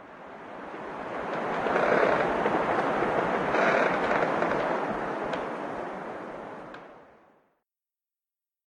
scpcb-godot/SFX/Ambient/Forest/ambient4.ogg at 8f5d2fcf9fe621baf3dc75e4253f63b56f8fd64b